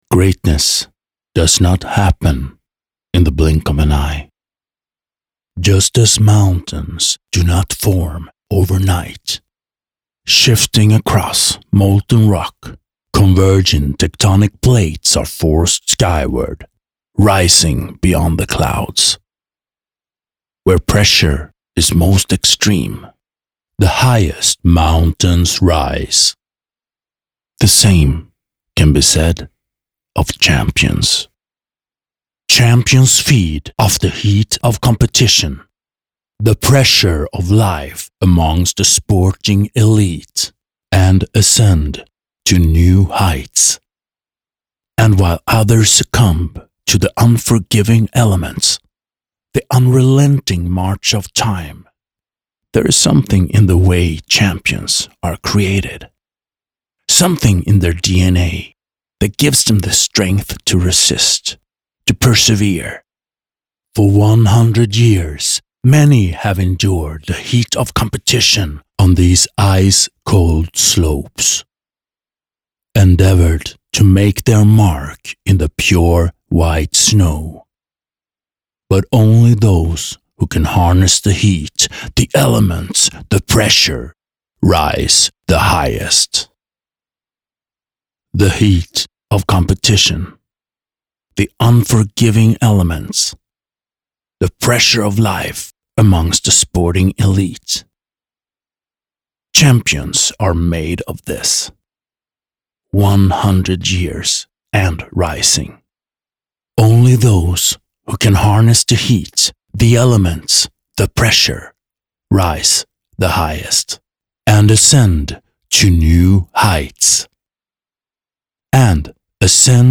Male
Confident, Warm, Versatile
E-LEARNING_WEB_VOICEOVERMEDLEY.mp3
Microphone: AKG c414b-uls, Shure SM7